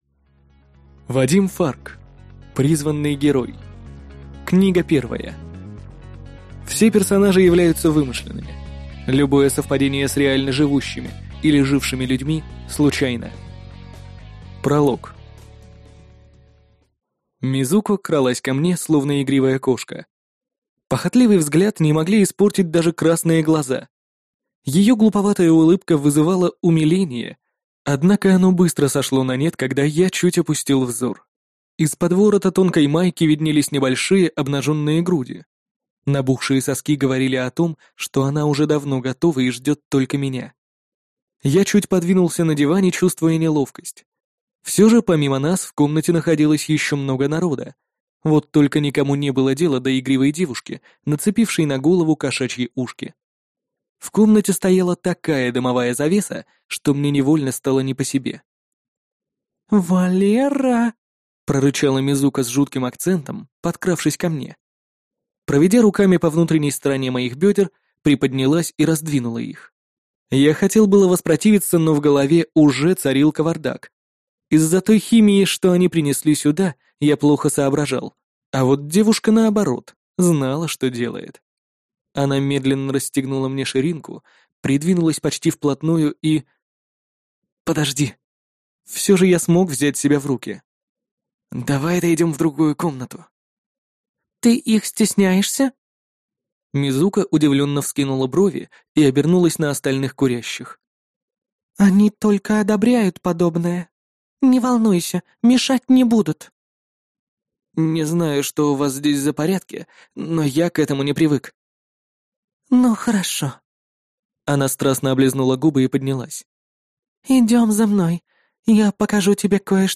Аудиокнига Призванный Герой. Книга 1 | Библиотека аудиокниг